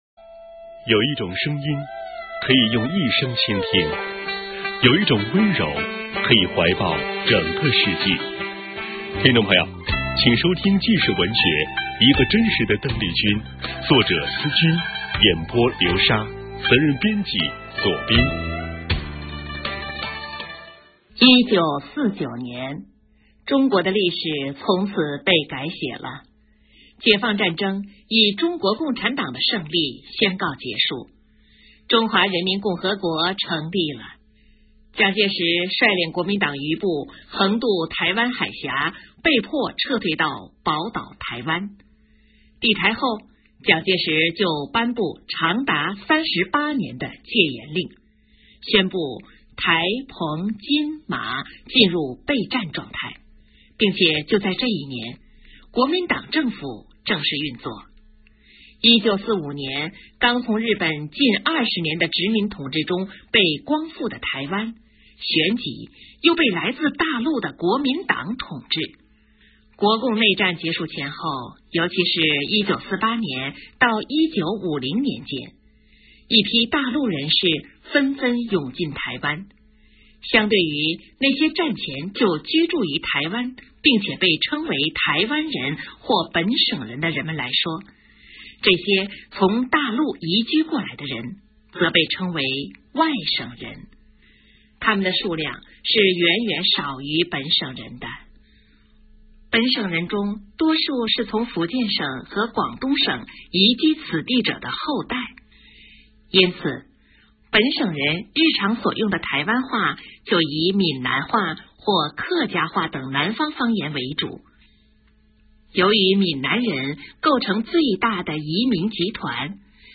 【有声文学】《美丽与哀愁:一个真实的邓丽君》